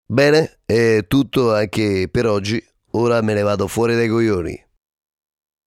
In un famoso programma d'intrattenimento, un imitatore si cimenta nel ‘Bisteccone’. Questo wave è una possibile e divertente alternativa sonora alla chiusura del Windows®.